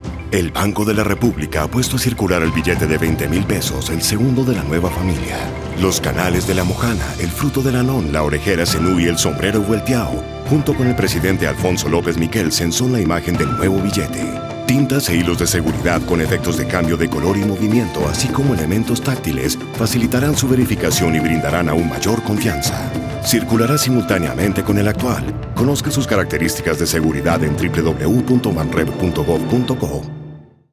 Cuña radial